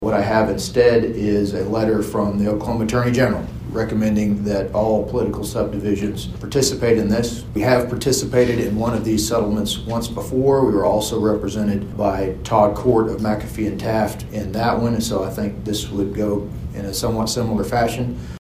The Bartlesville City Council approved participation in an opioid settlement agreement during a special meeting on Monday.
City Attorney Jess Kane presented the resolution for the city to participate in the Oklahoma Opioid Distributor Settlement after